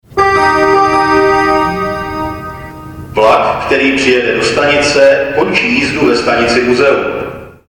- Staniční hlášení o příjezdu soupravy směr Muzeum si